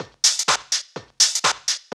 B2HOUSE123.wav